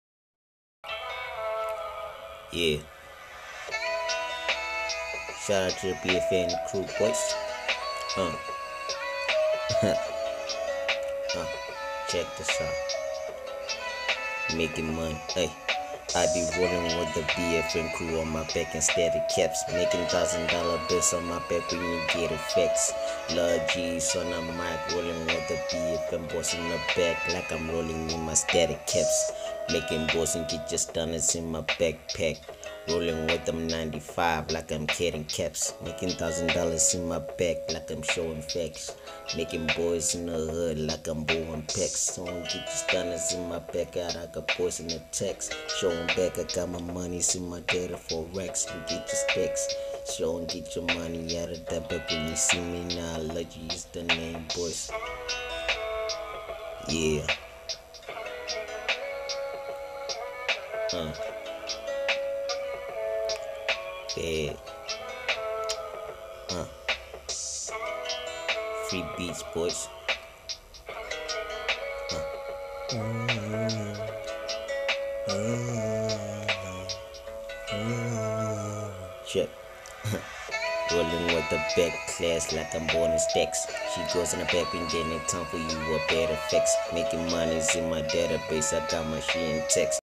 01:30 Genre : Hip Hop Size